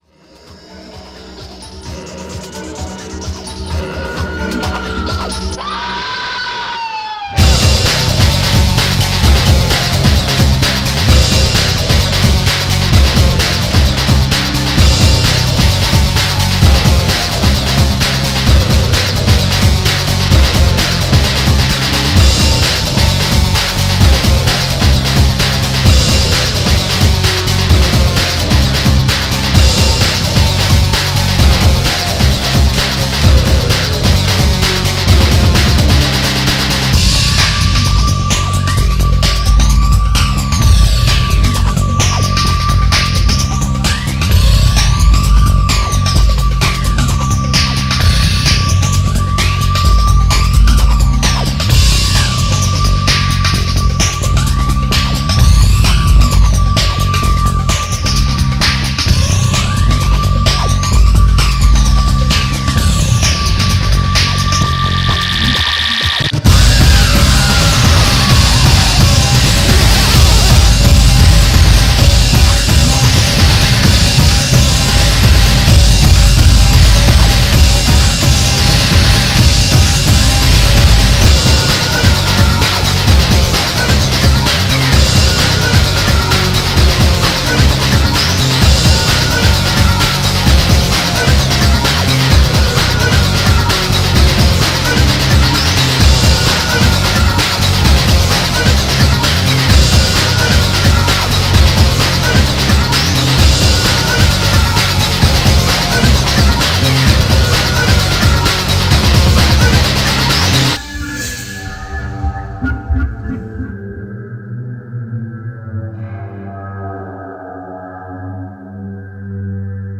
BPM130
Audio QualityMusic Cut